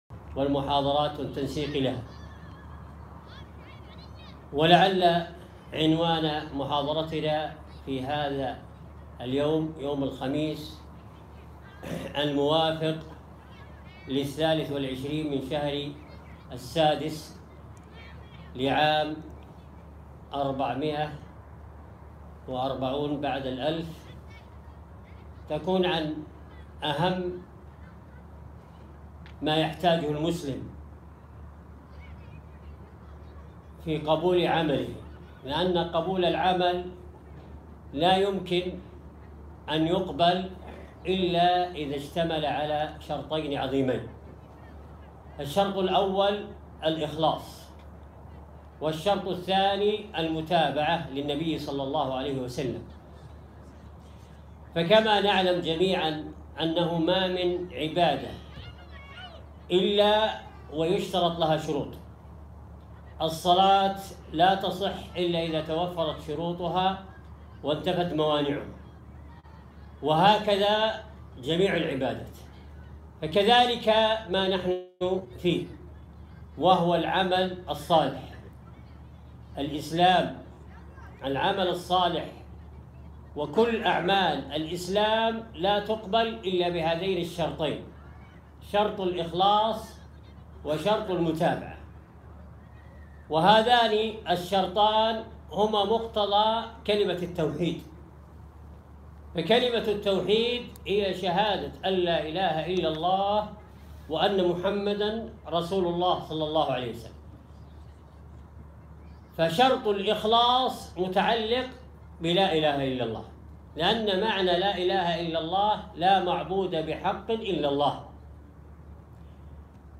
محاضرة - الإخلاص